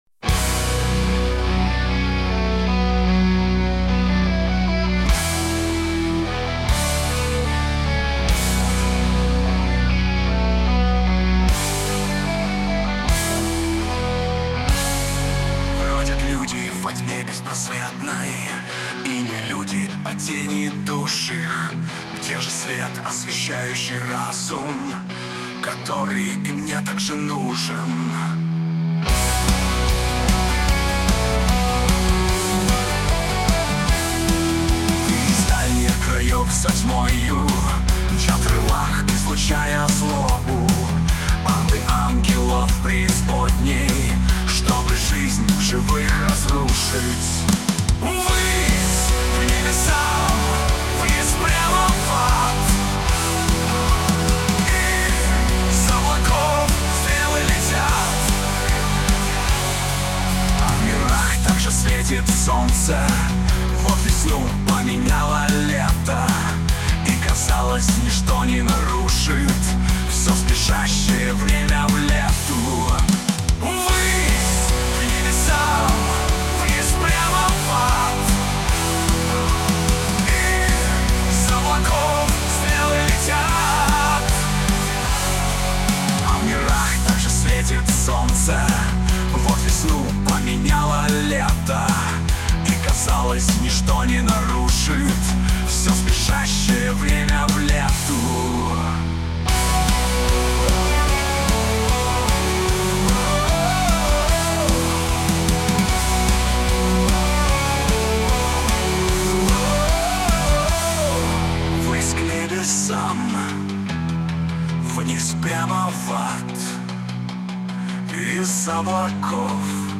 • Жанр: Рок